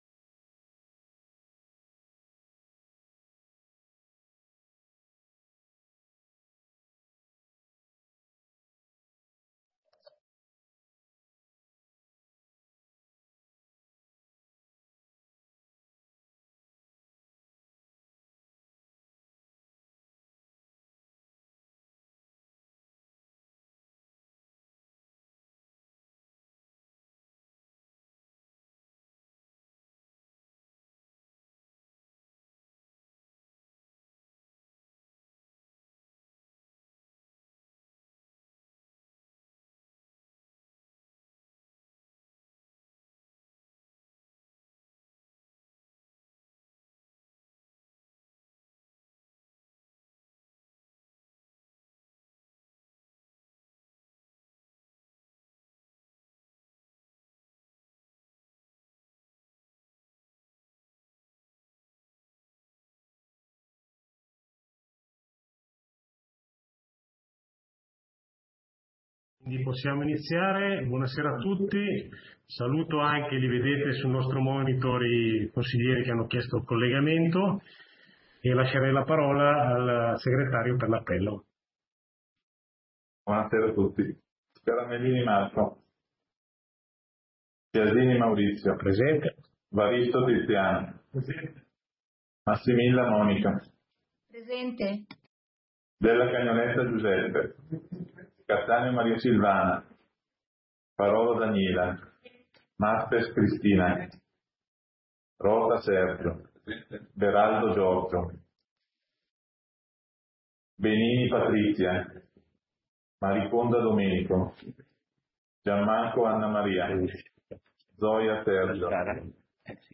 Seduta consiglio comunale del 29 aprile 2022 - Comune di Sondrio
Ordine del giorno ed audio della seduta consiliare del Comune di Sondrio effettuata nella data sotto indicata.